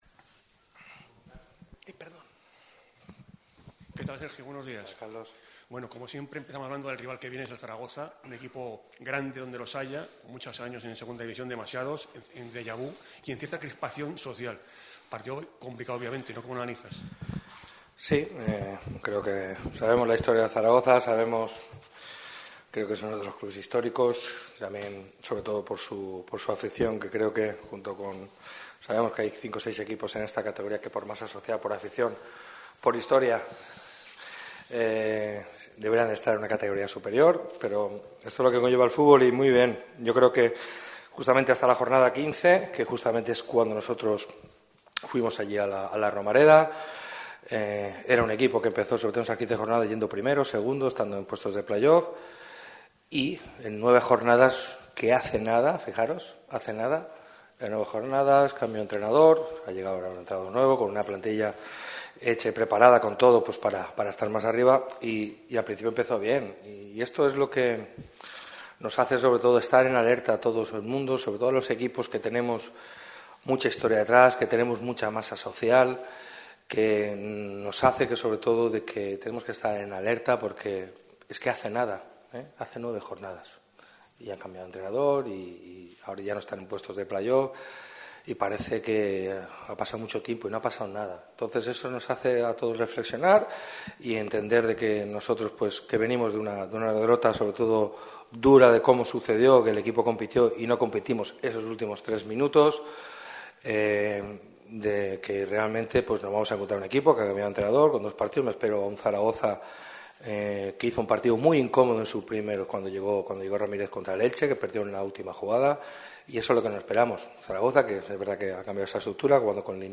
El técnico del Málaga CF, Sergio Pellicer, ha comparecido en la sala de prensa de La Rosaleda en el mediodía de este viernes en la previa del partido del domingo ante el Real Zaragoza.